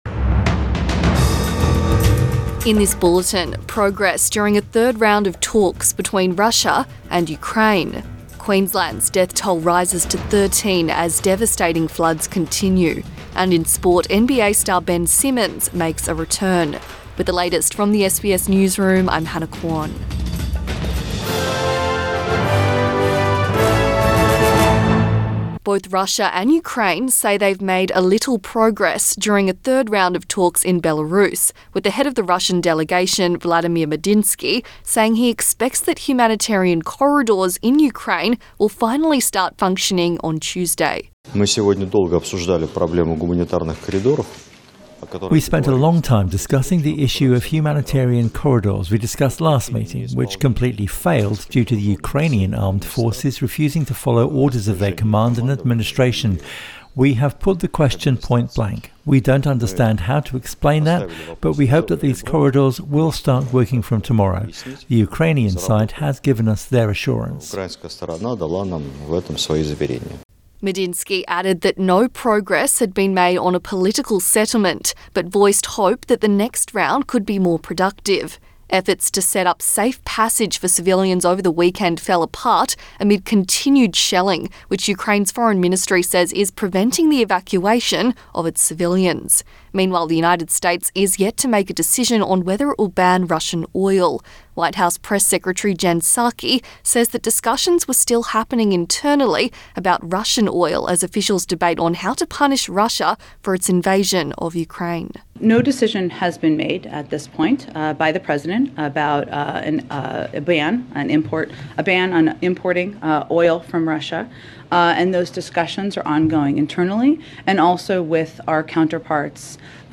Midday bulletin 8 March 2022